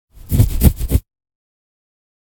scratch.ogg